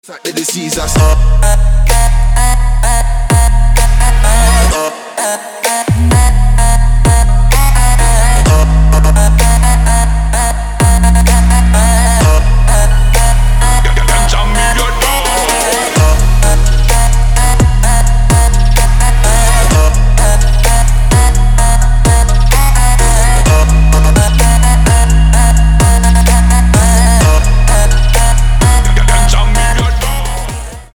• Качество: 256, Stereo
Electronic
Trap
Bass
Tribal Trap